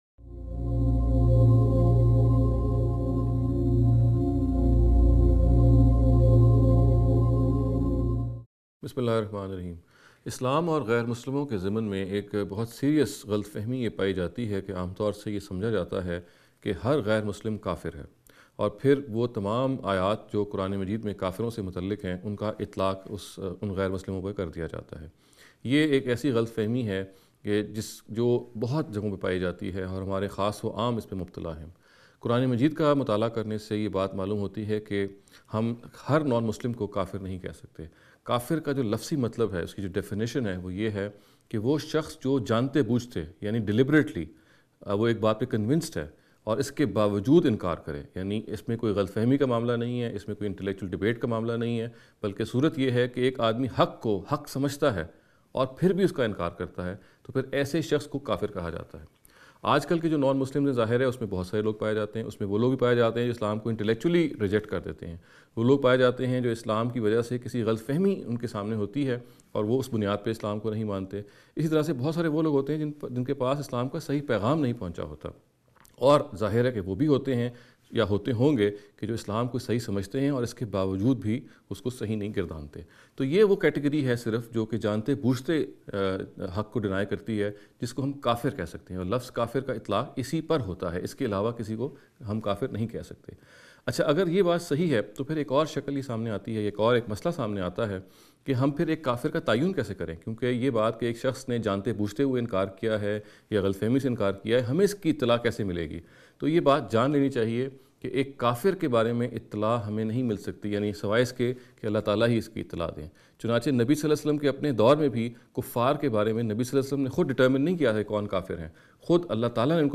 This lecture series will deal with some misconception regarding the Islam and Non-Muslims.